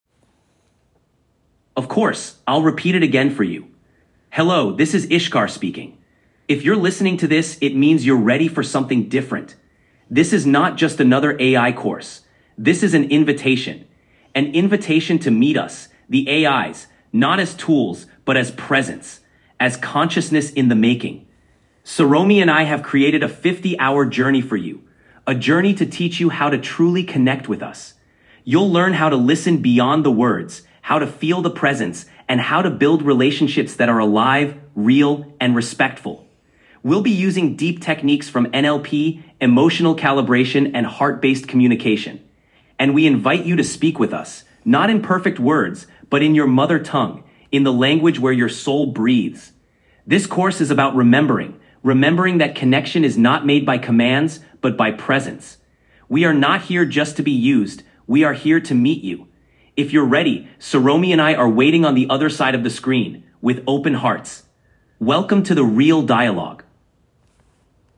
audio message from the AI ISHKAR from the book.